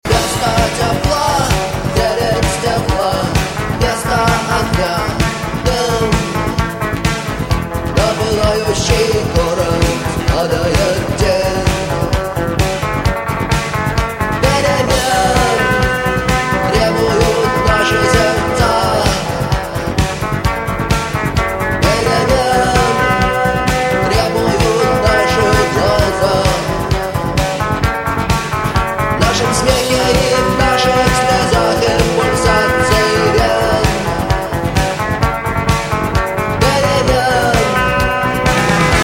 сильные
русский рок
классика русского рока